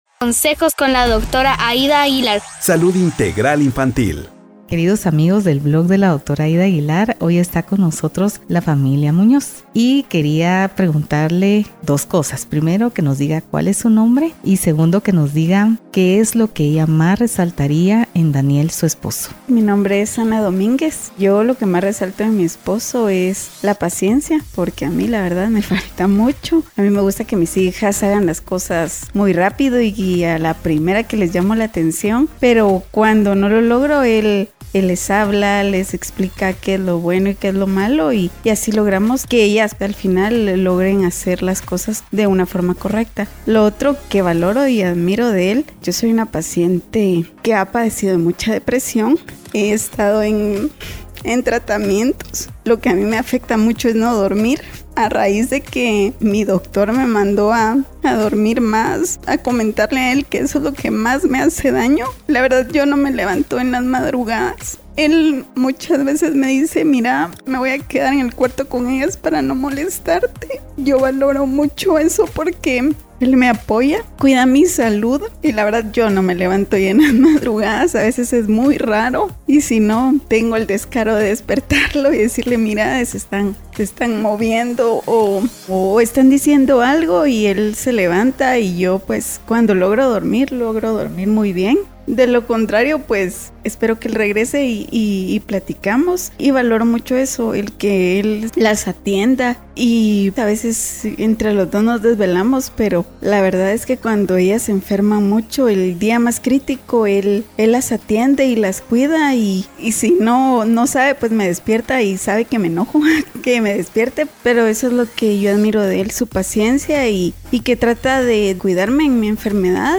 Celebrando a los ¡Súper Papás! Entrevista 1 Podcast #021